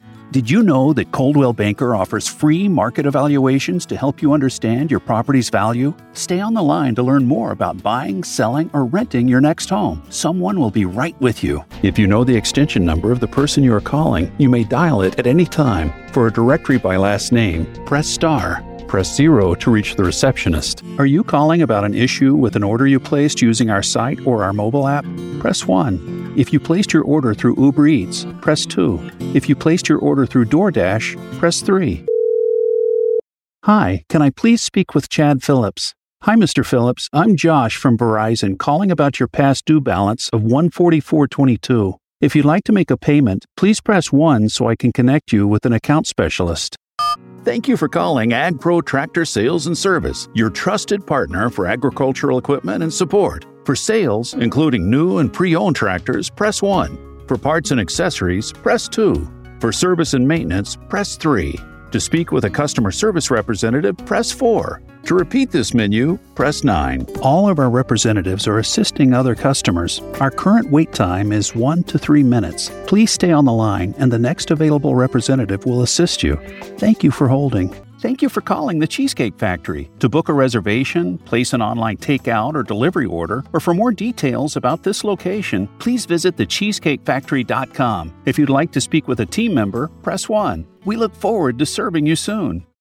Naturelle, Distinctive, Polyvalente, Amicale, Chaude
Téléphonie